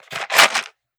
Ammo Pickup 001.wav